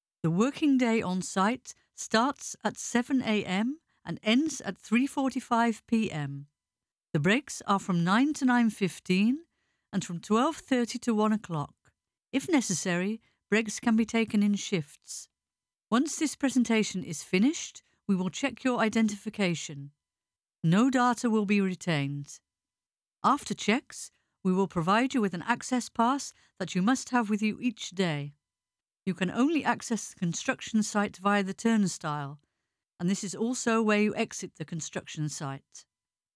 De voicing wordt per dia opgenomen in onze eigen studio. Opvolgend wordt de audio bewerkt zodat het goed te volgen is, ook in een drukke bouwkeet.
• Voice-over veiligheidsinstructies